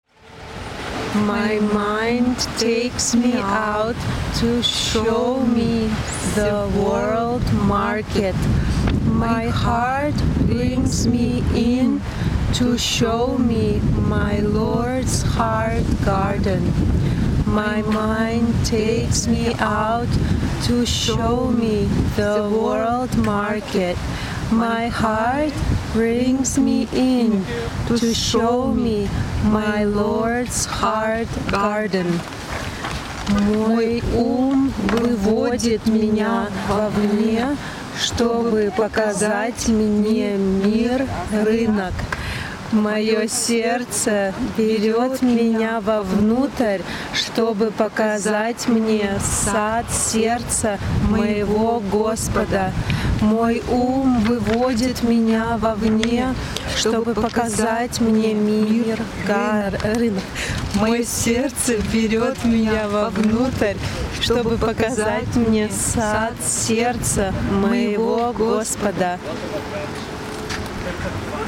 Poem of the day read in English and Russian